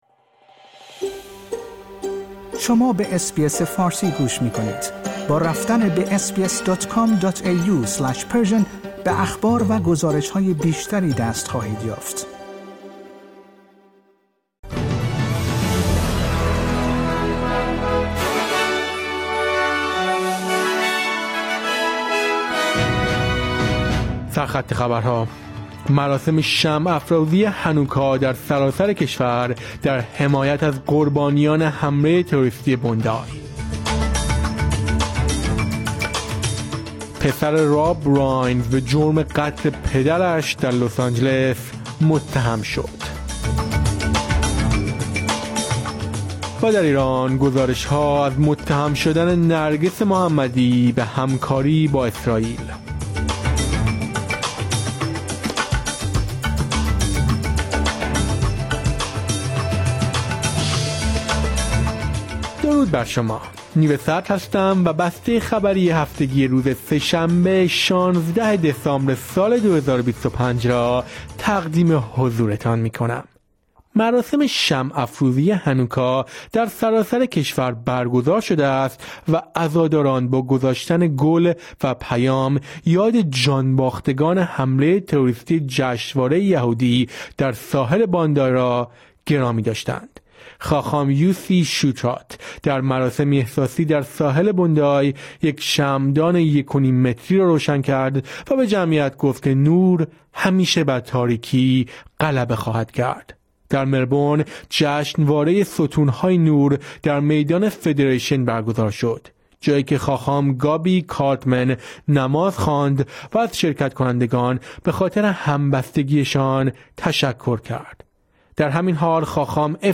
در این پادکست خبری مهمترین اخبار هفته منتهی به سه‌شنبه ۱۶ دسامبر ۲۰۲۵ ارائه شده است.